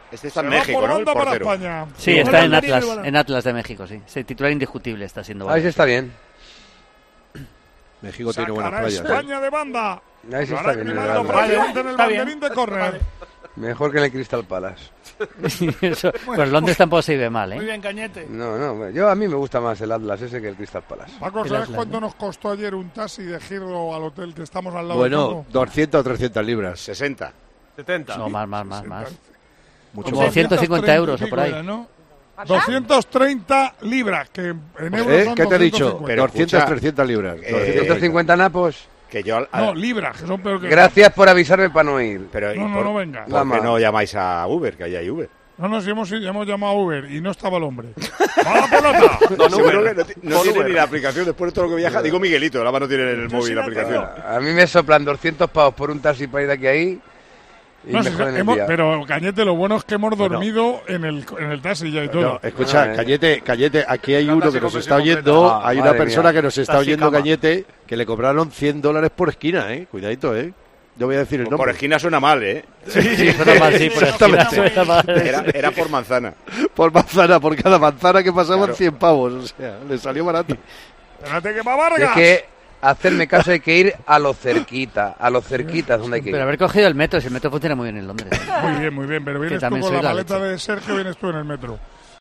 Dentro de esa retransmisión del partido en Tiempo de Juego, Manolo Lama y Miguel Ángel desvelaron que les costó 230 Libras un taxi desde el Aeropuerto Internacional de Heathrow. Lama bromeó sobre este aspecto y señaló que "hasta hemos dormido ahí".